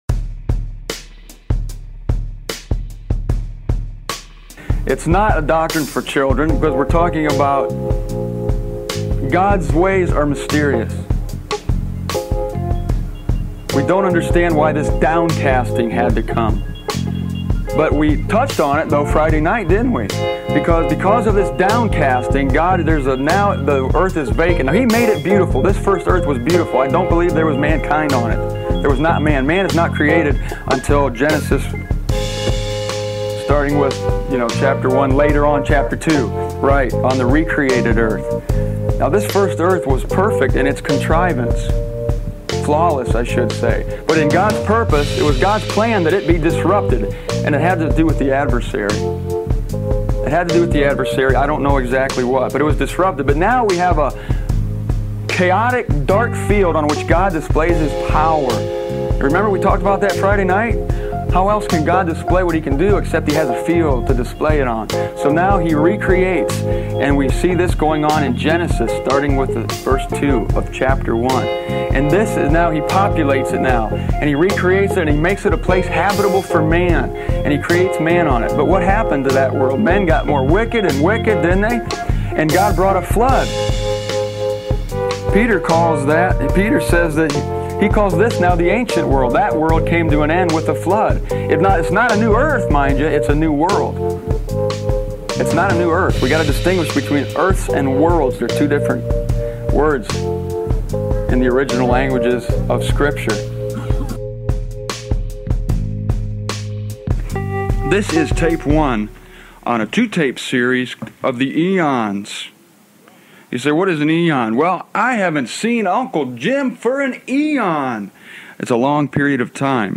I was the only speaker at a three-day conference.